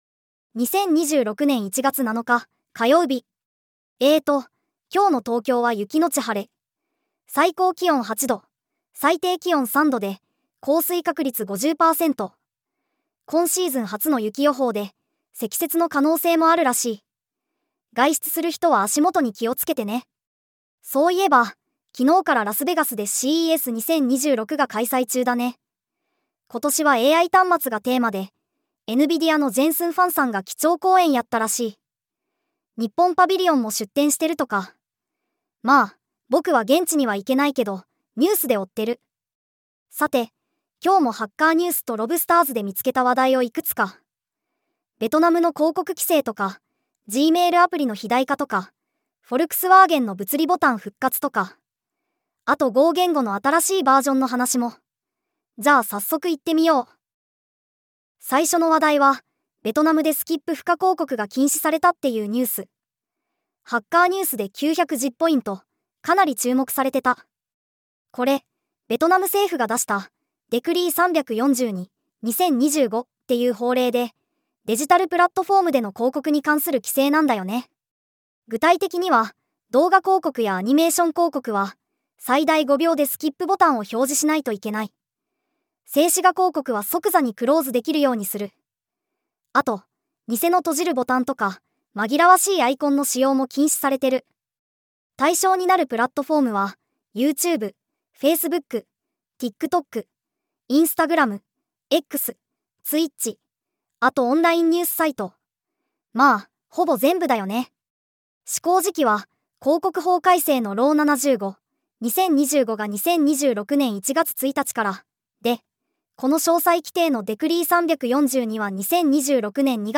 テトさんに技術系ポッドキャストを読んでもらうだけ
※ このポッドキャストは LLM により生成されています。
音声: VOICEPEAK 重音テト キャラクター